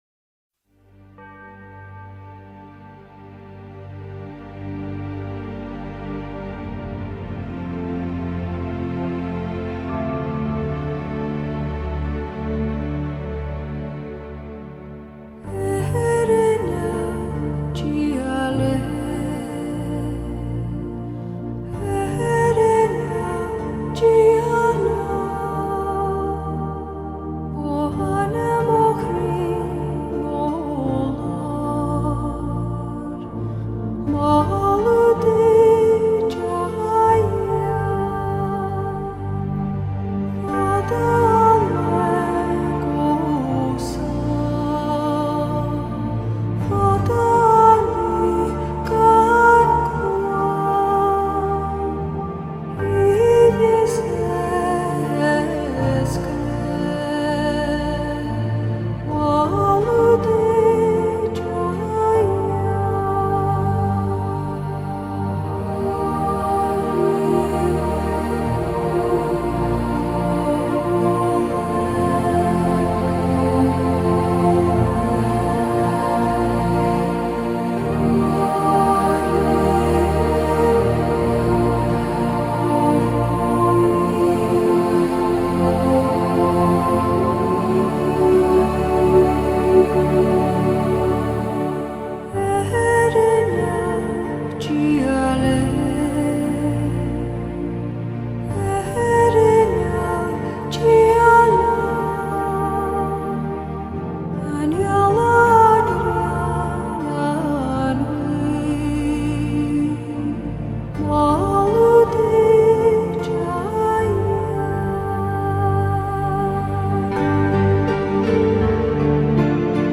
KFiuQIWpdSf_musica-para-estudiar-concentrarse-leer-descansar-pensar-meditar-hacer-tareas.mp3